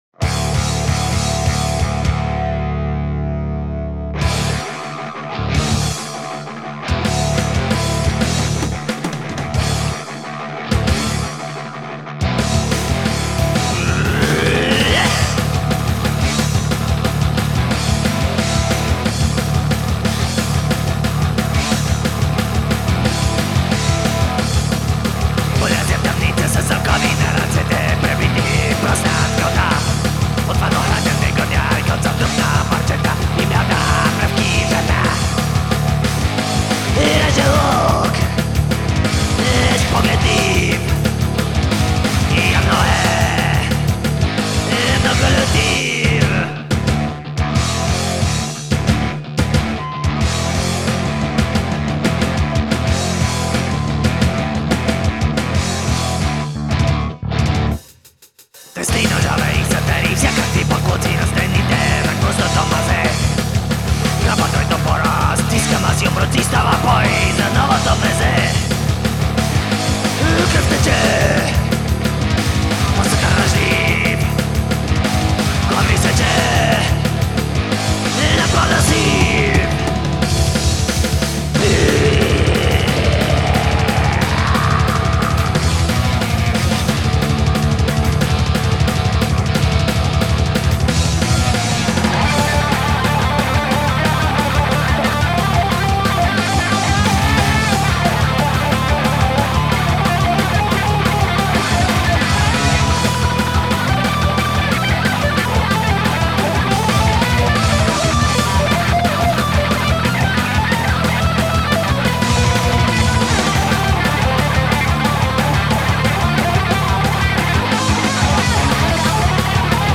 petrifying thrash metal song